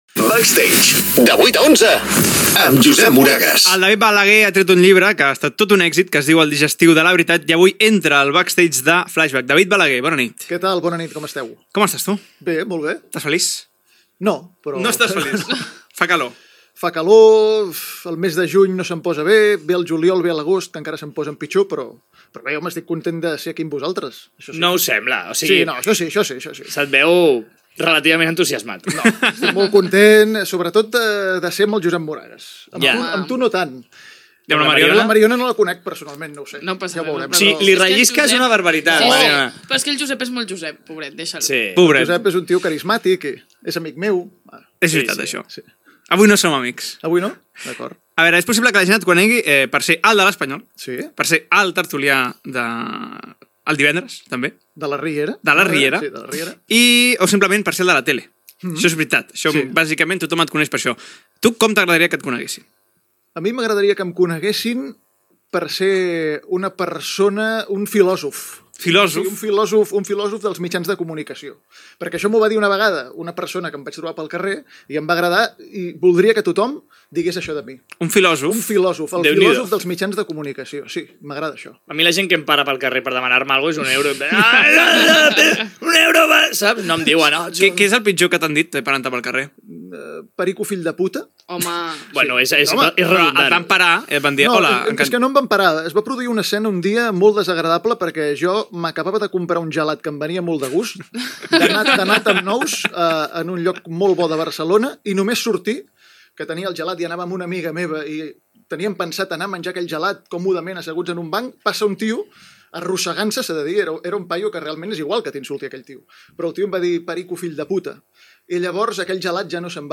Indicatiu del programa, entrevista